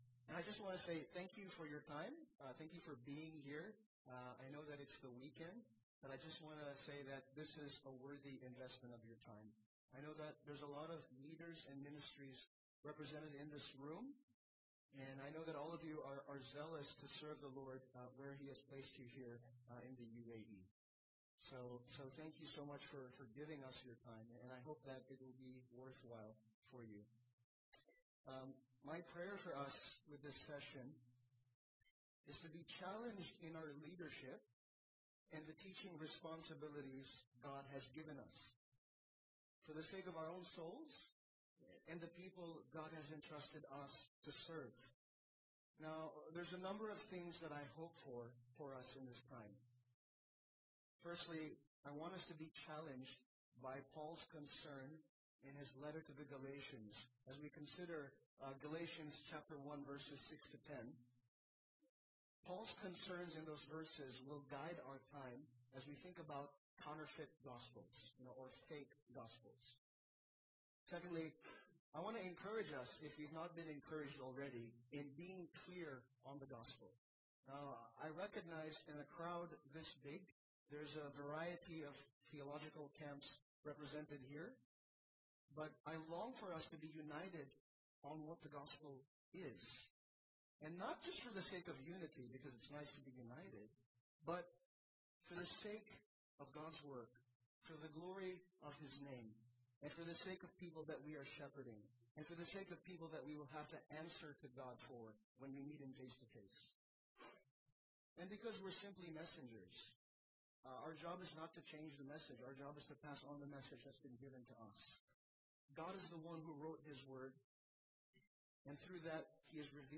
A message from the series "Wala nang Ibang Gospel."